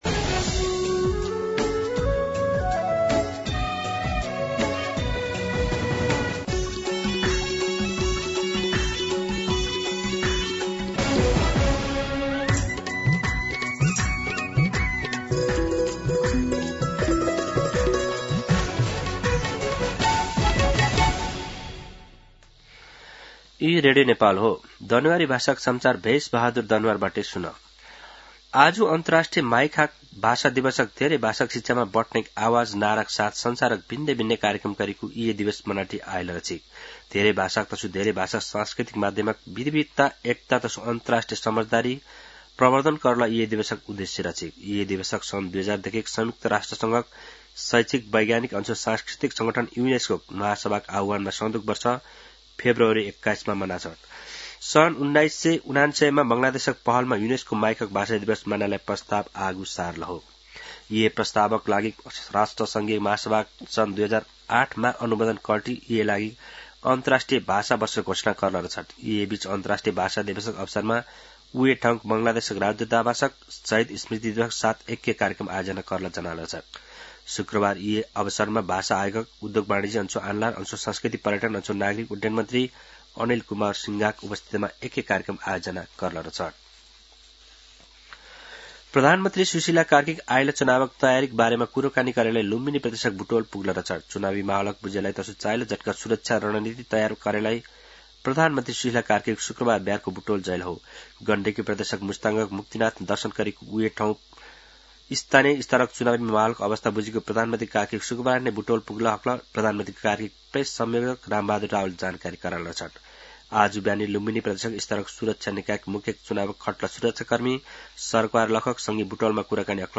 दनुवार भाषामा समाचार : ९ फागुन , २०८२
Danuwar-News-09-1.mp3